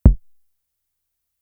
Index of /90_sSampleCDs/300 Drum Machines/Fricke Schlagzwerg/Kicks
Kick (7).WAV